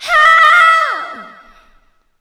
SCREAM3   -R.wav